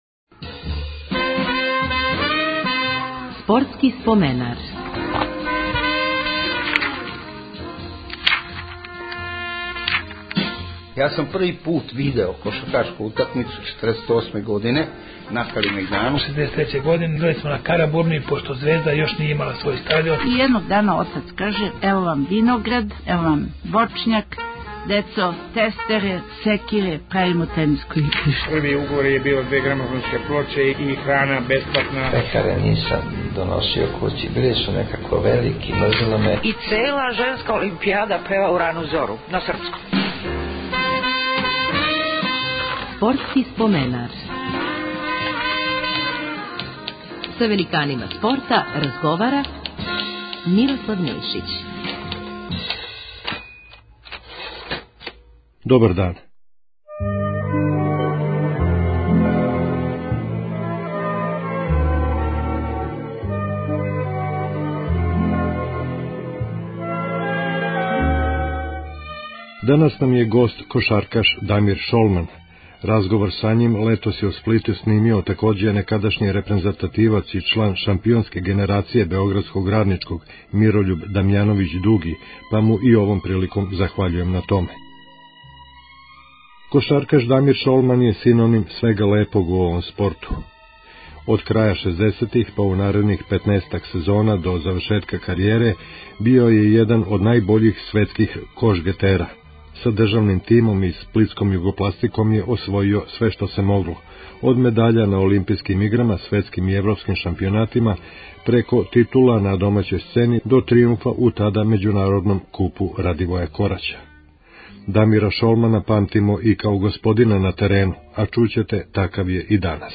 Гост 284. емисије је Дамир Шолман, један од најтрофејнијих југословенских кошаркаша.